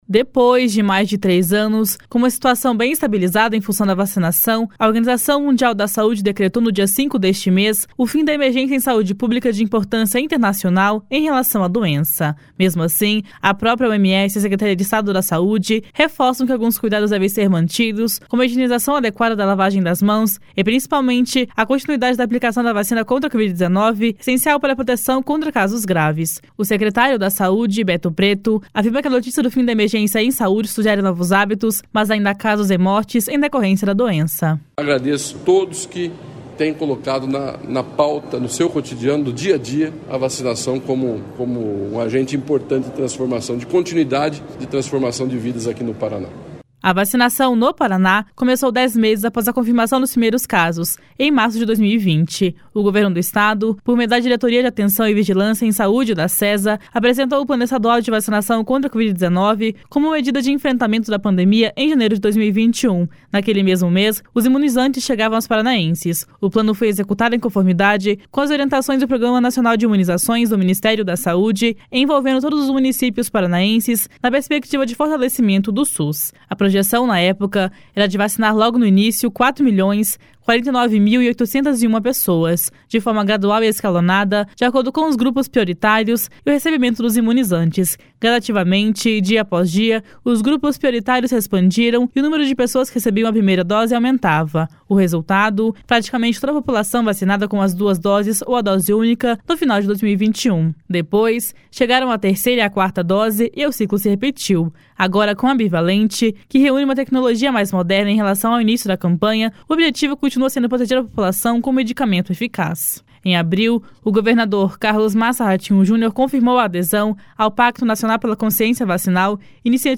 Mesmo assim, a própria OMS e a Secretaria de Estado da Saúde reforçam que alguns cuidados devem ser mantidos, como a higienização adequada da lavagem das mãos e, principalmente, a continuidade da aplicação da vacina contra a Covid-19, essencial para a proteção contra casos graves. O secretário da Saúde, Beto Preto, afirma que a notícia do fim da Emergência em Saúde sugere novos hábitos, mas ainda há casos e mortes em decorrência da doença. // SONORA BETO PRETO //